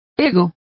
Complete with pronunciation of the translation of self.